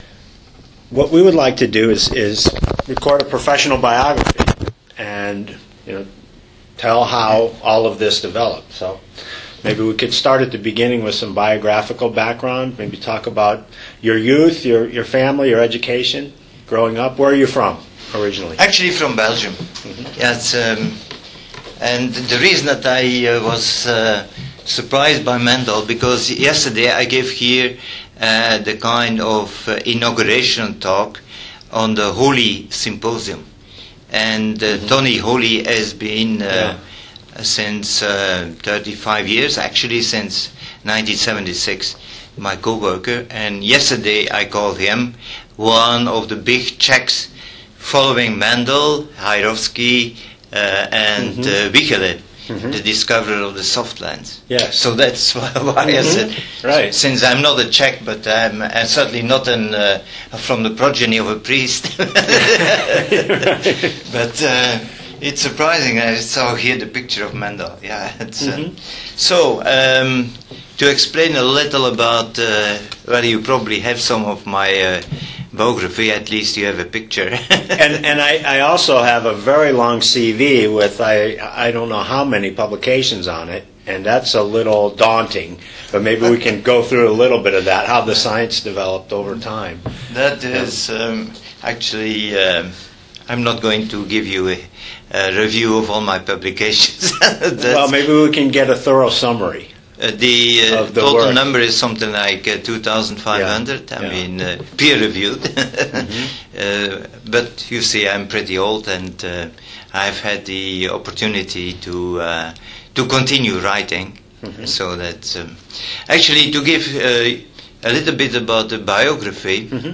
Research interview with Erik De Clercq